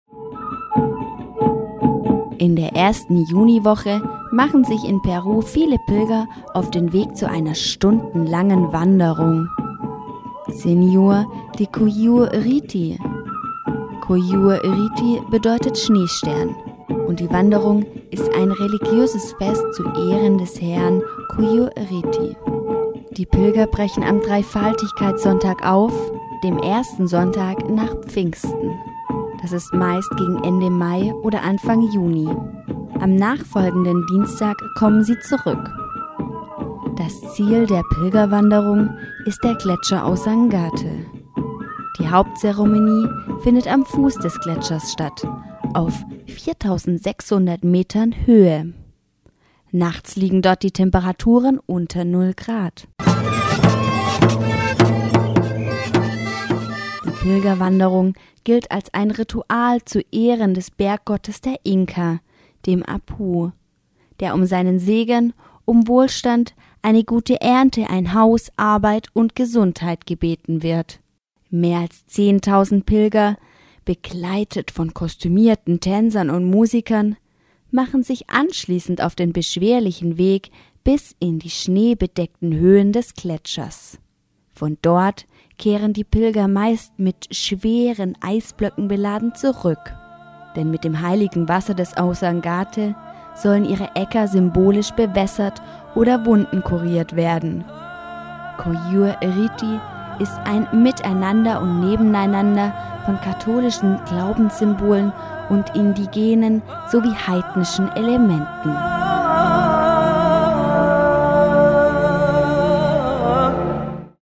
Wir berichteten über deutsche und peruanische Feierlichkeiten. Hier ein Bericht über die peruanische Pilgerwanderung Quyllur-Riti: